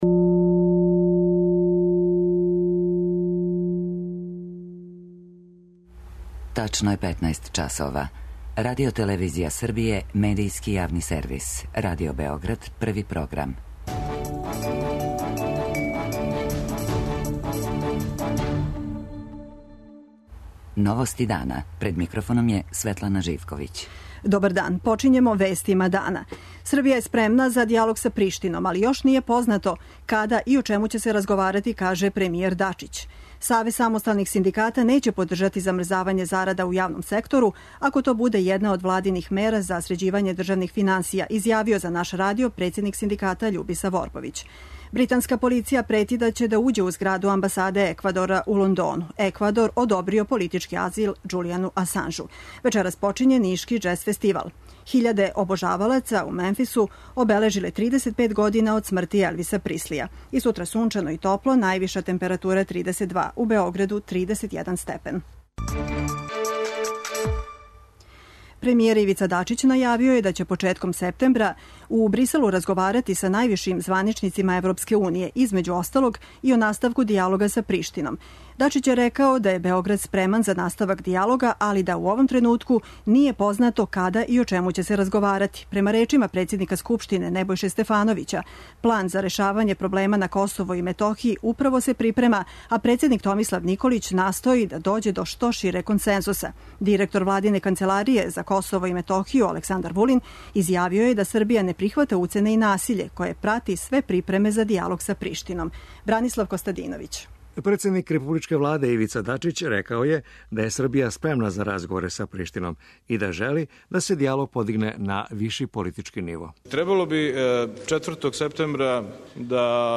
О овоме, за Београд 1, говори Бранко Ковачевић, ректор Београдског универзитета.
преузми : 15.70 MB Новости дана Autor: Радио Београд 1 “Новости дана”, централна информативна емисија Првог програма Радио Београда емитује се од јесени 1958. године.